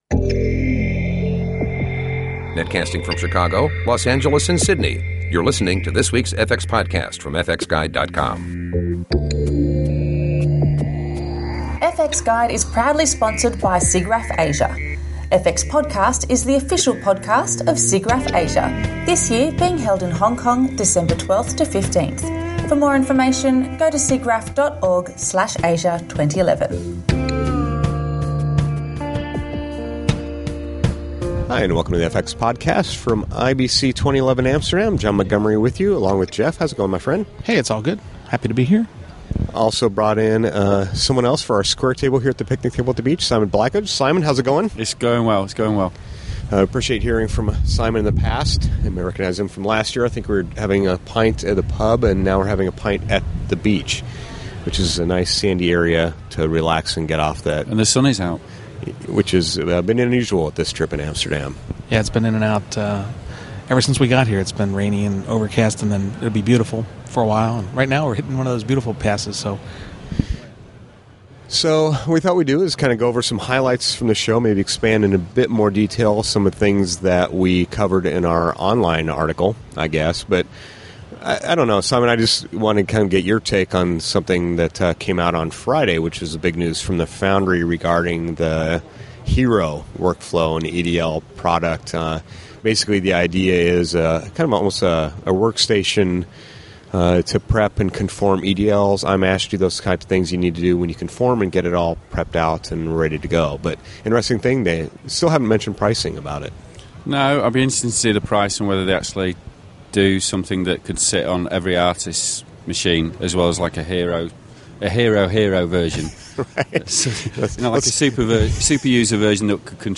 Recorded live at IBC 2011 in Amsterdam, a roundtable discussion about the show